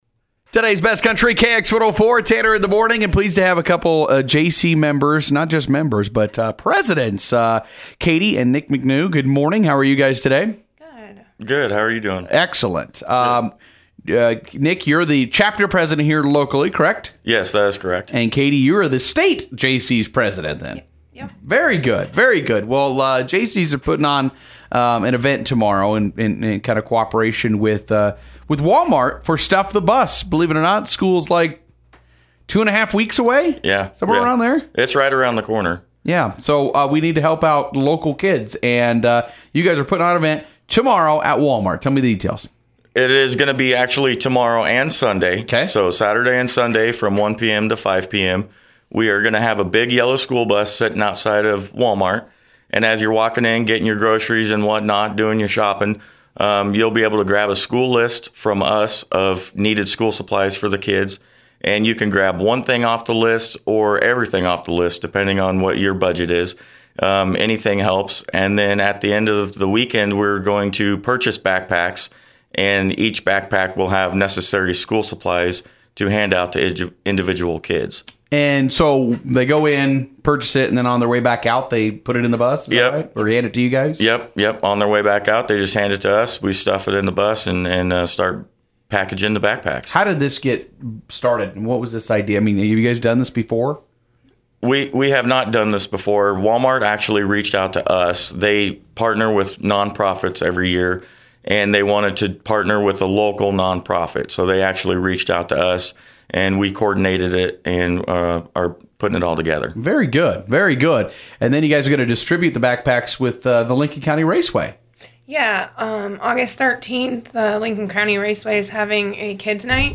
Our full interview